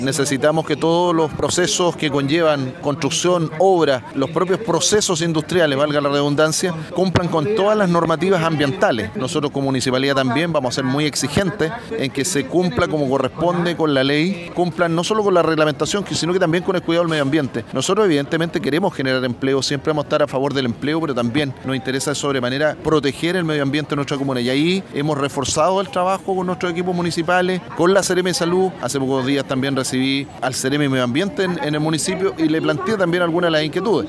Consultado por el tema, el alcalde de Lota, Jaime Vásquez, valoró el proyecto de la pesquera, dada las oportunidades de empleo que puede generar. Sin embargo, espera que sus obras no afecten al entorno del sector y “tengan respeto por el medioambiente”.
alcalde-planta-alimentos-marinos.mp3